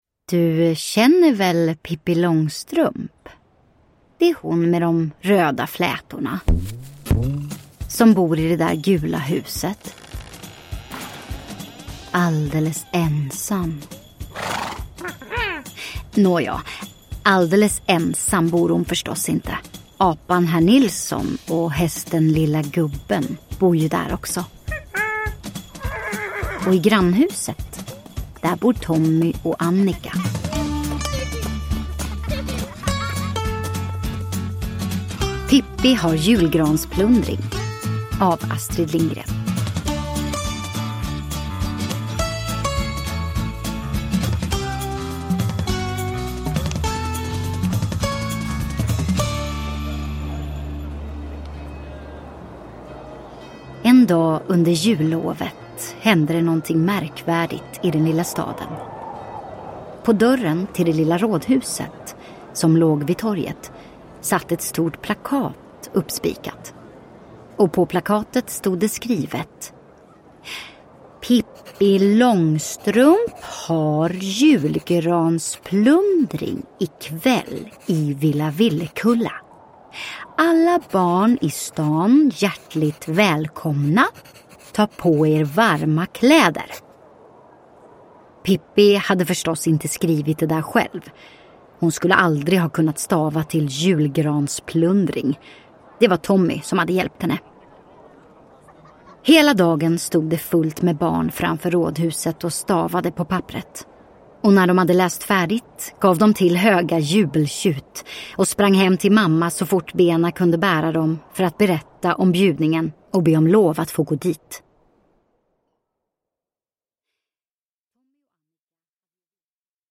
Pippi har julgransplundring (Ljudsaga) – Ljudbok